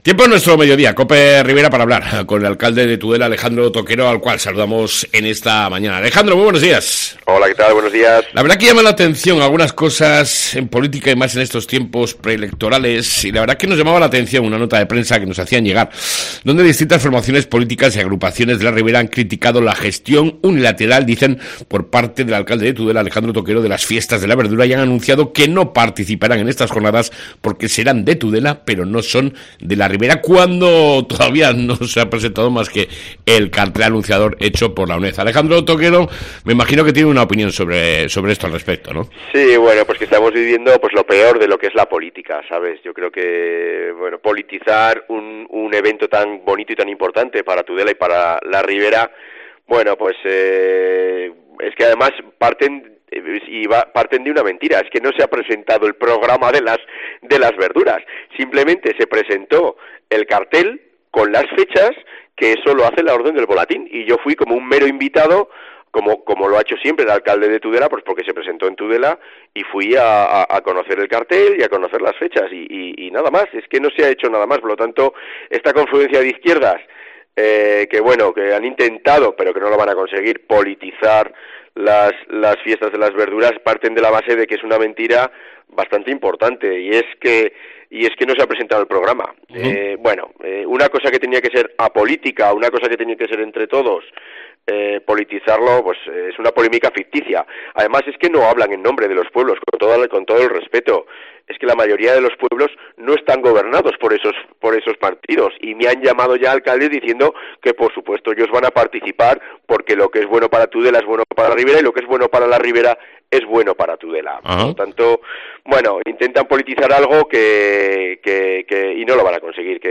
ENTREVISTA CON ALEJANDRO TOQUERO, ALCALDE DE TUDELA